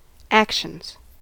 actions: Wikimedia Commons US English Pronunciations
En-us-actions.WAV